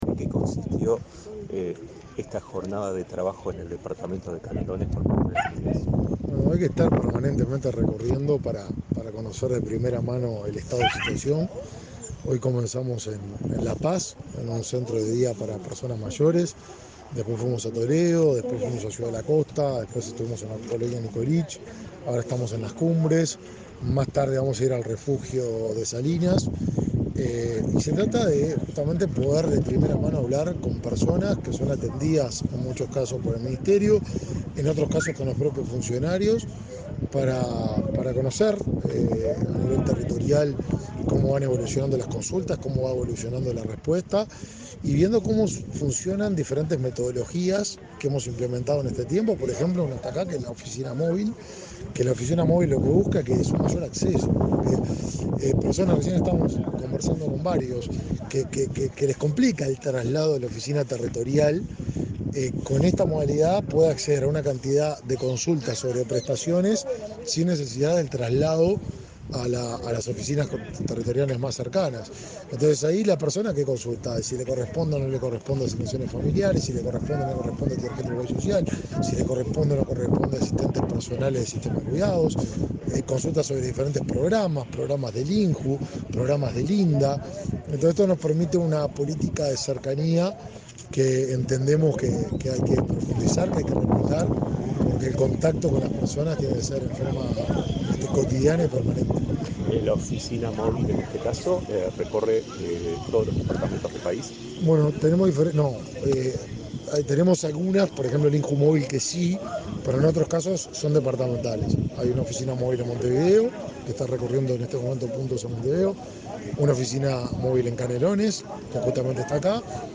Entrevista al ministro de Desarrollo Social, Martín Lema
El ministro Martín Lema recorrió, este 17 de agosto, el departamento de Canelones.